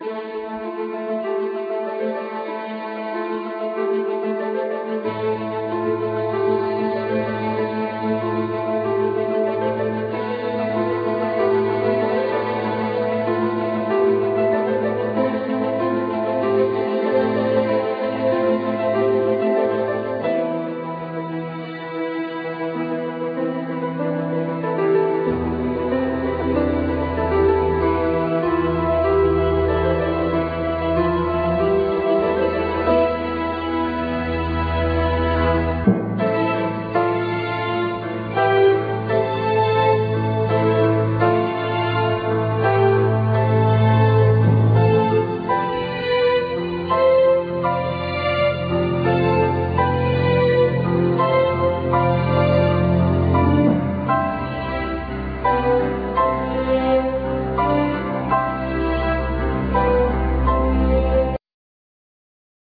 Soprano
Mezzo Soprano
Tennor
Piano
Orchestra